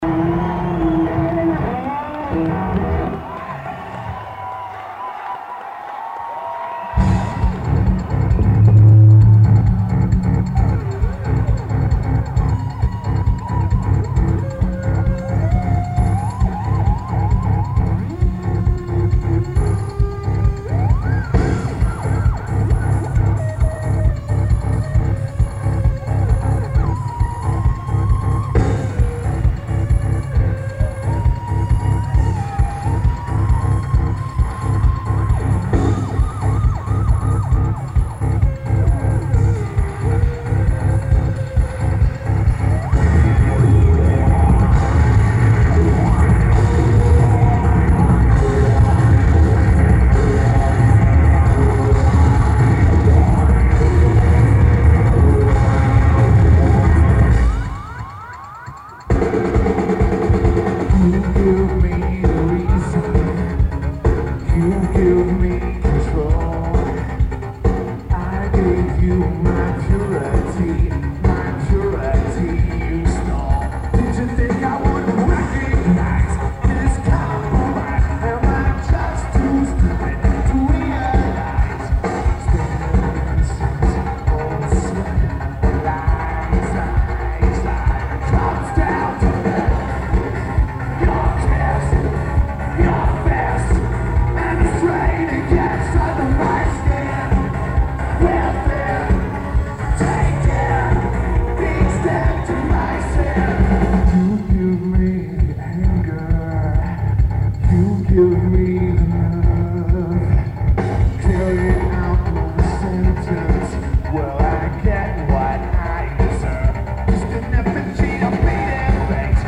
Brixton Academy
Lineage: Audio - AUD, Unknown Purchased Bootleg Cassette
Few songs fade out, or fade in at the beginning.